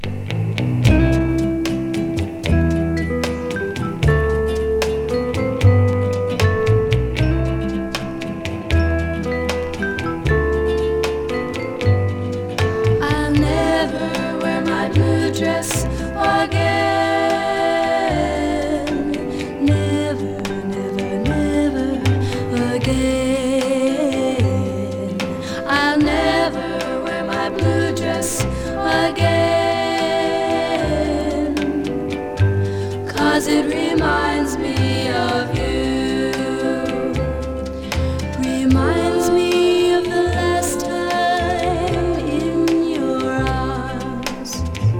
Pop, Girl　USA　12inchレコード　33rpm　Mono